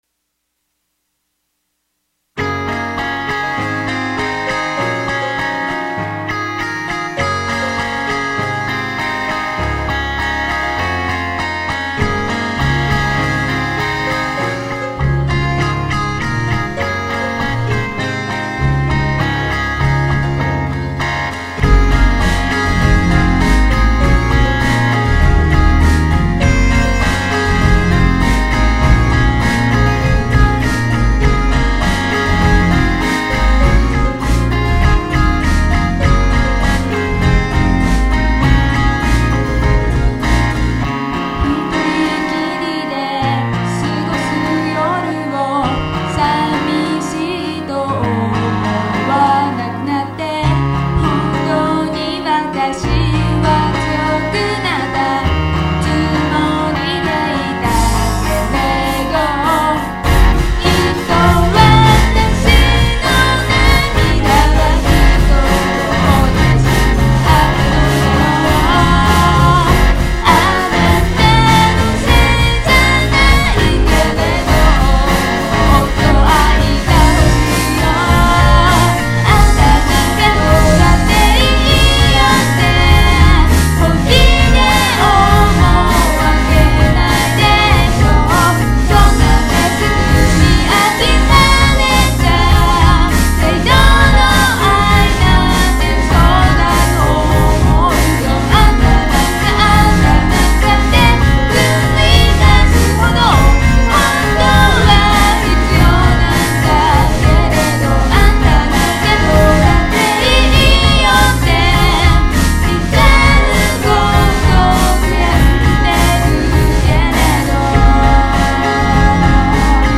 music compositions
SY85 is an all-in-one synthesizer produced by YAMAHA in 1992.
My first and longest experience of multitrack sequencer was on SY85.